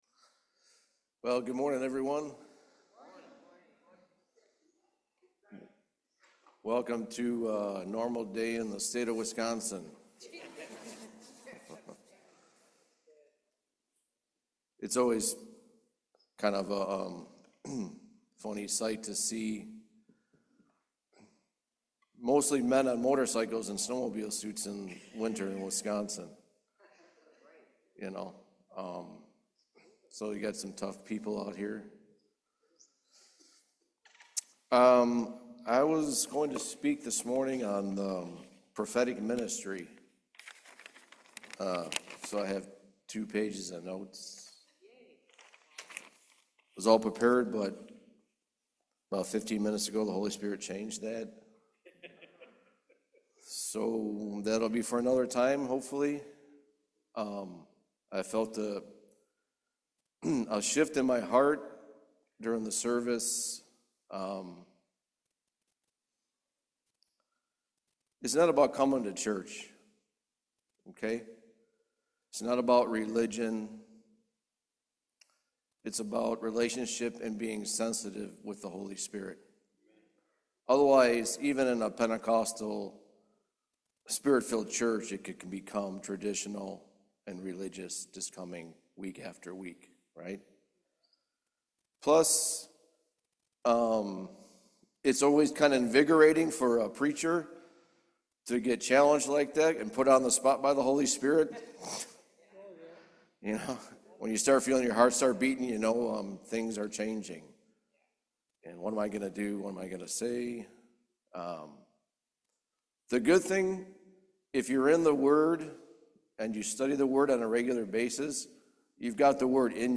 John 1:17 Service Type: Main Service Grace is a gift.